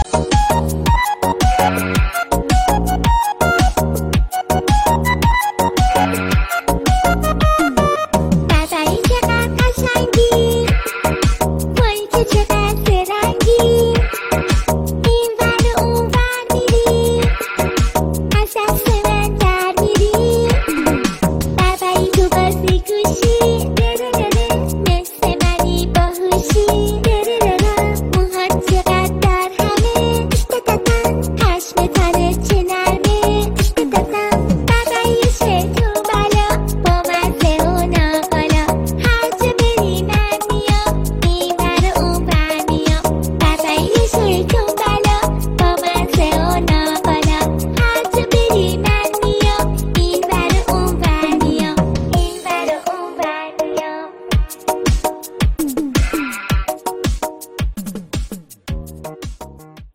شاد کودکانه